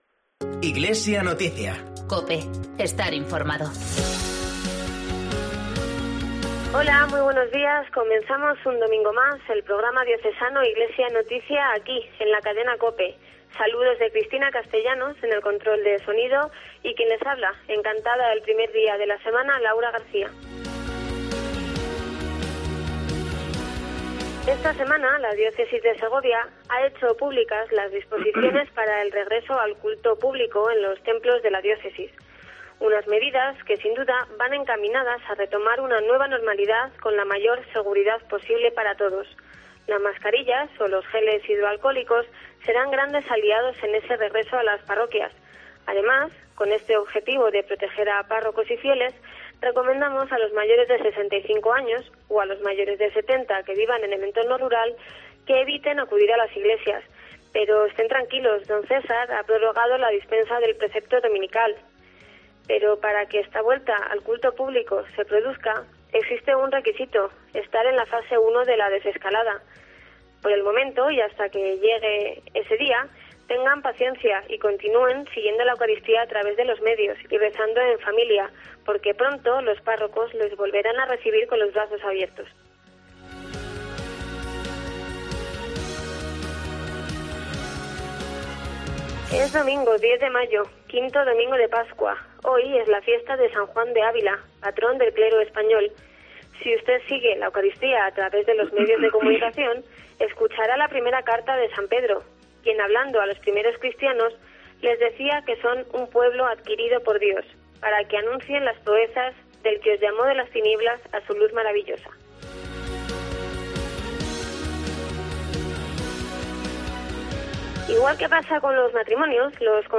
PROGRAMA RELIGIOSO